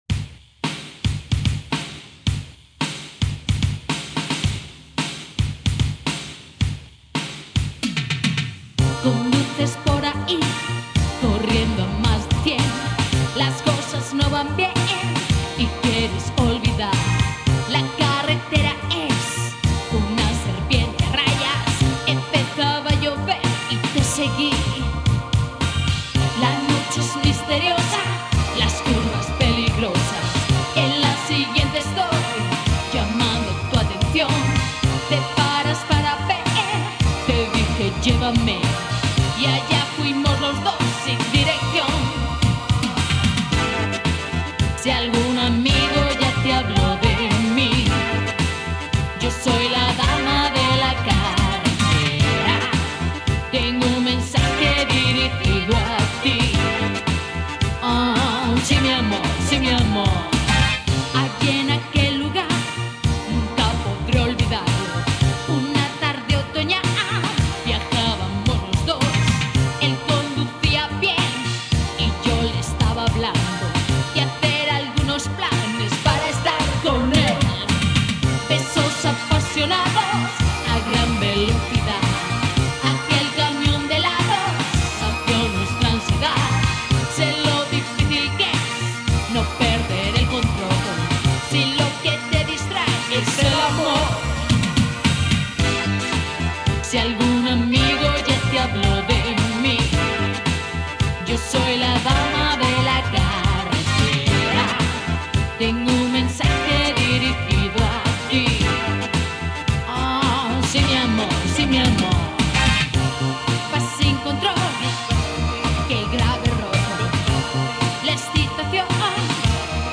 pero su voz era muy "agresiva" muy Rockera. Además, su imagen en foto era también transgresora, así que los temas tenían algo de mujer fatal, mezcla de Funky y Rock.